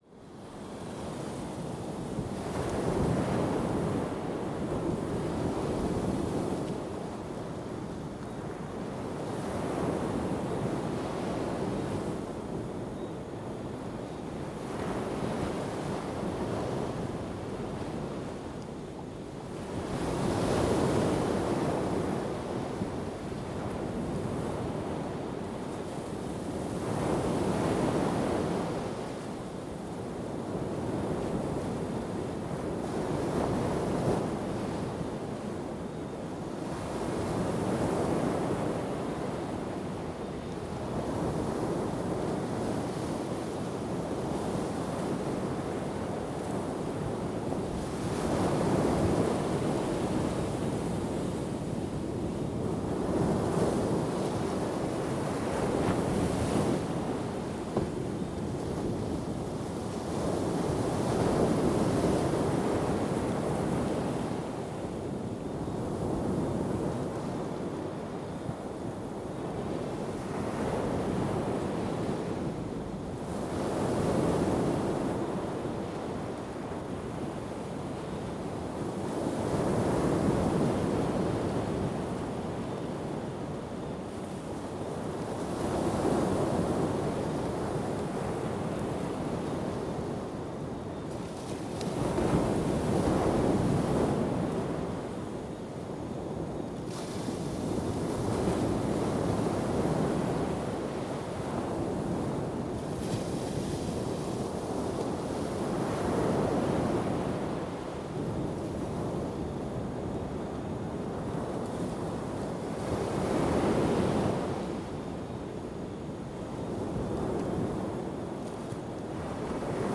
Здесь вы найдете успокаивающие композиции из шума прибоя, пения цикад и легкого ветра — идеальный фон для отдыха, работы или сна.
Звук океанских волн на закате солнца записано в Ямайке